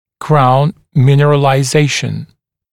[kraun ˌmɪnərəlaɪ’zeɪʃn][краун ˌминэрэлай’зэйшн]коронковая минерализация